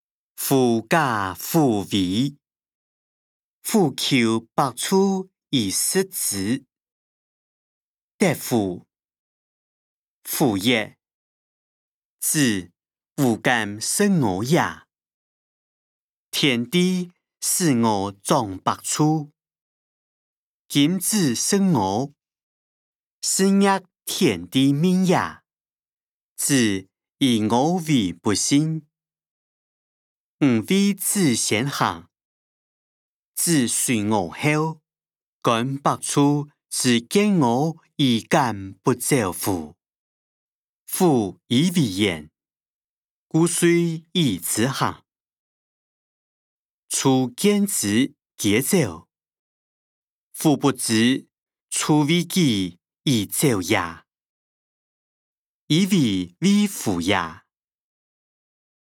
歷代散文-狐假虎威音檔(四縣腔)